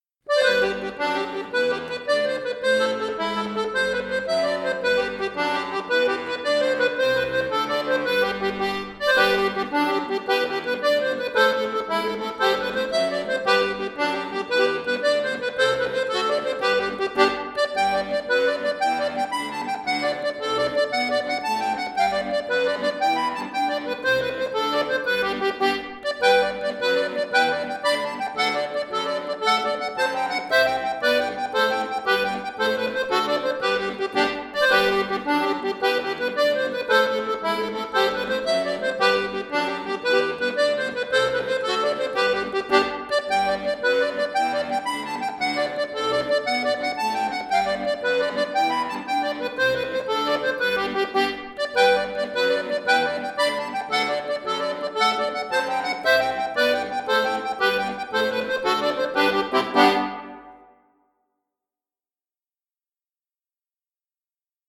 Klassiker des irischen Folk